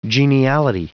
Prononciation du mot geniality en anglais (fichier audio)
Prononciation du mot : geniality